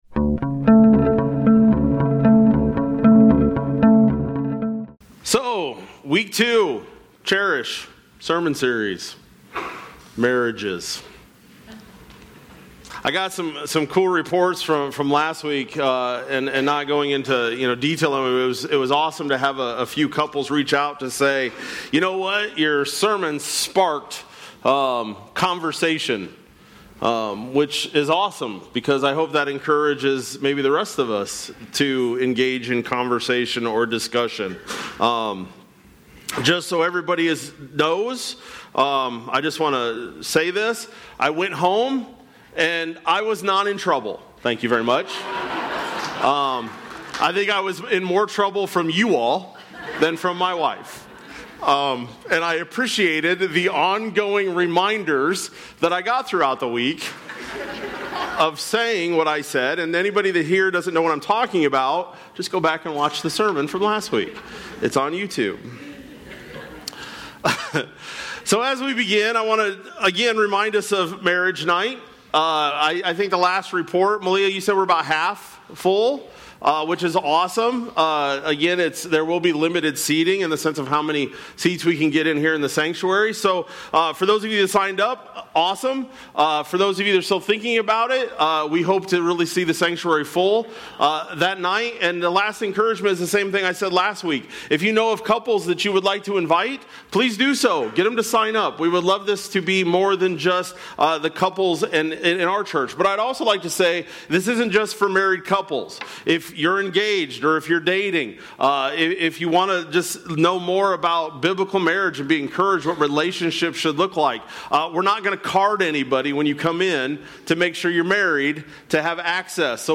Sept-14-25-Sermon-Audio.mp3